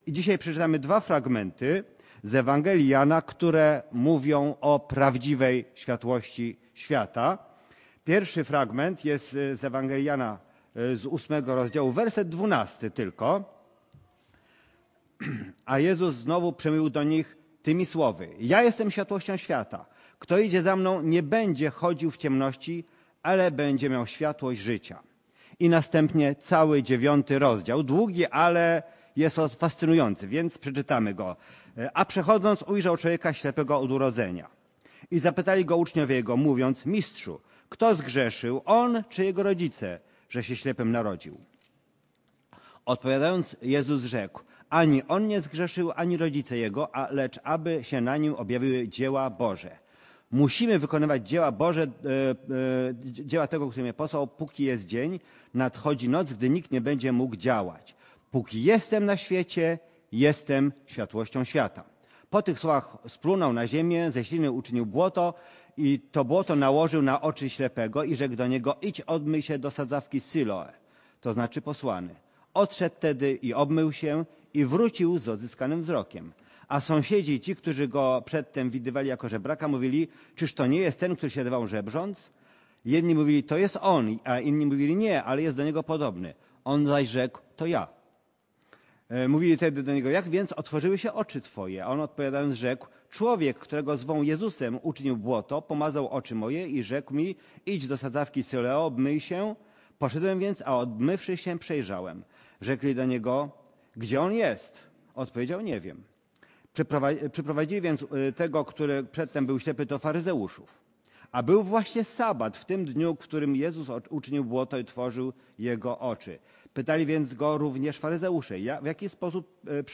Kazanie
wygłoszone na nabożeństwie w niedzielę 14 grudnia 2025 r. Tematy: adwent , Jezus , światłość